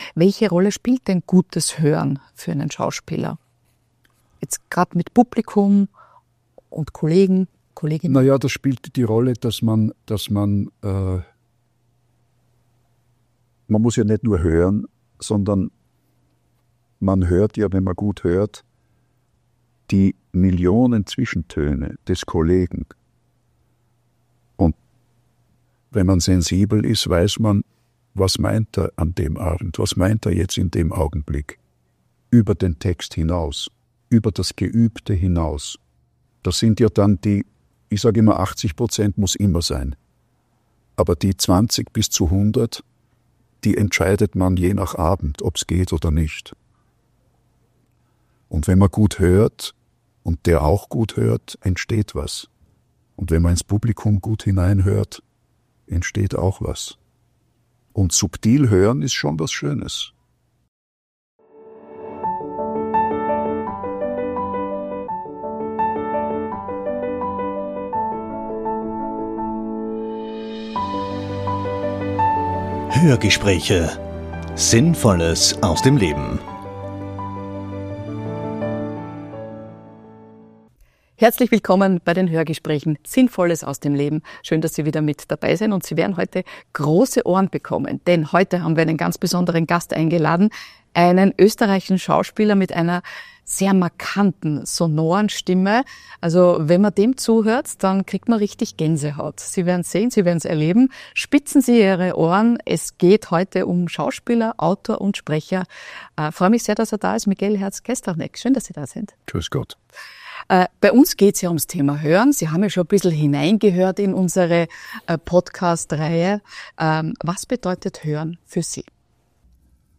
Herz-Kestranek spricht auch über Exil als wichtigen Teil seiner Familiengeschichte und über Zurücklassung dessen, was einen ausmacht. Ein eindrucksvolles Gespräch über Stimme, Resonanz und das, was zwischen den Worten liegt.